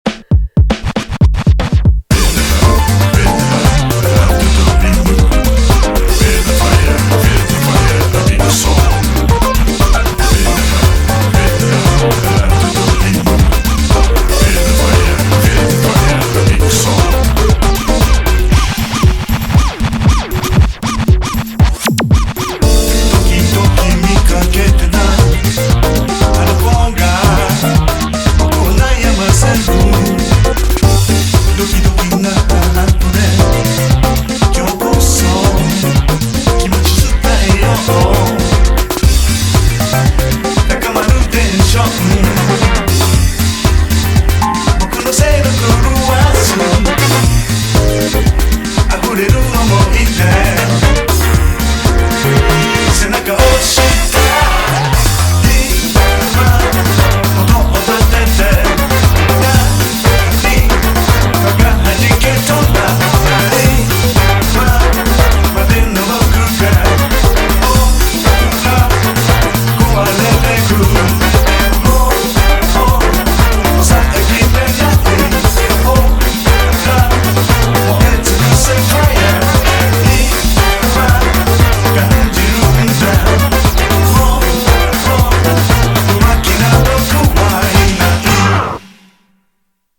BPM117
Audio QualityPerfect (High Quality)
A groovy J-Acid song
with a special guest vocalist appearance!